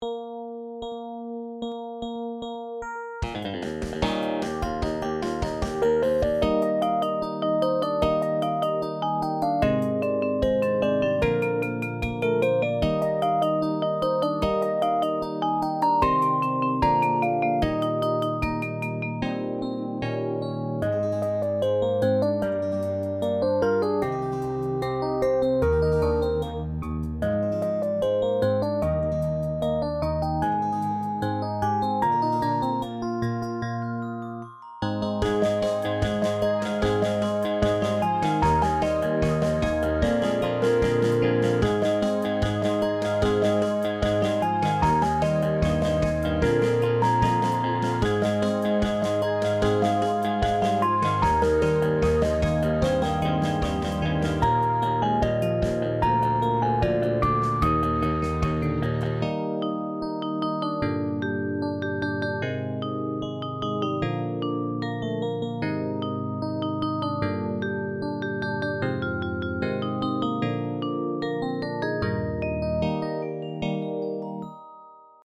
インストゥルメンタルエレクトロニカポップ明るい
BGM